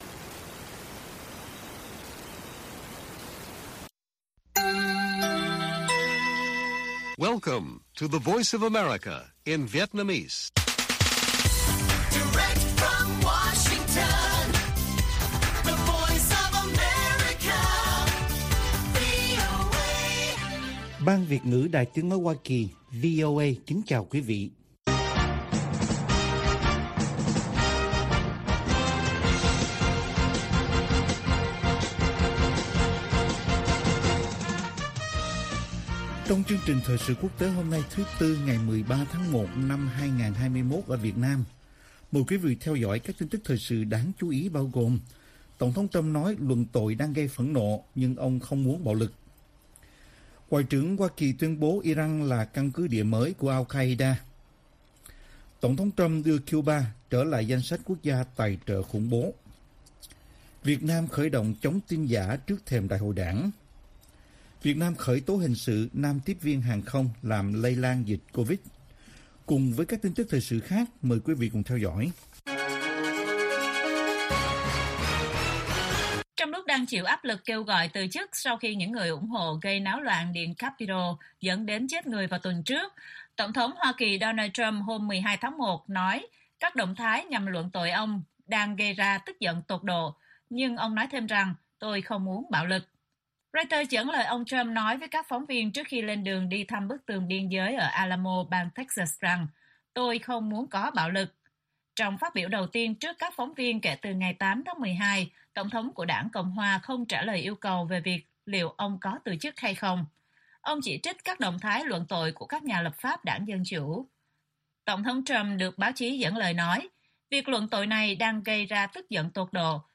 Bản tin VOA ngày 13/1/2021